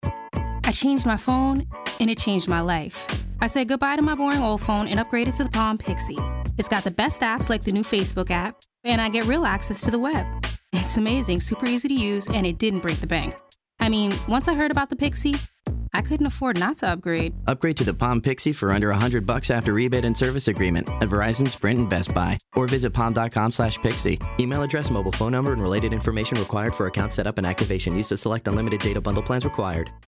African American, Bilingual, Singer, Fun, Urban, Proper, Teen, Disney, Nickelodeon, Southern, Hip, Sassy, Sweet, Clear
Sprechprobe: Industrie (Muttersprache):
Naturally a Disney/Nickelodeon type of voice. HIGH ENERGY, young and FUN!!